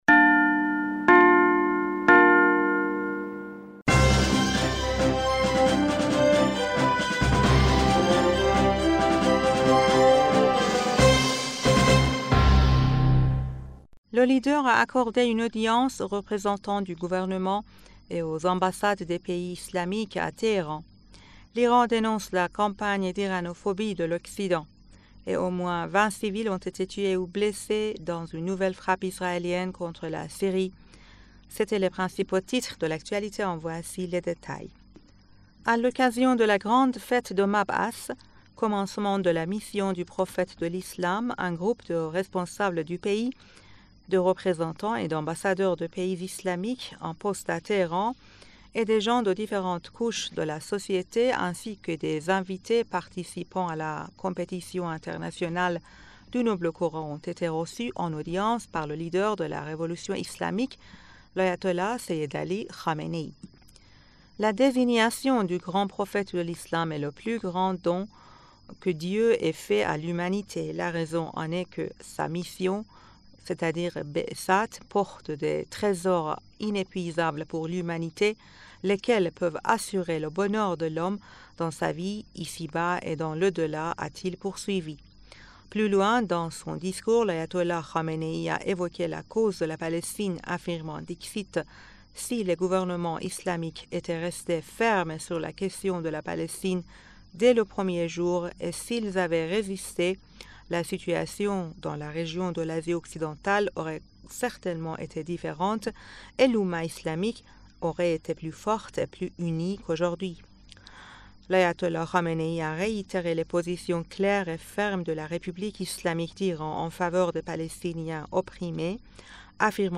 Bulletin d'information du 19 Février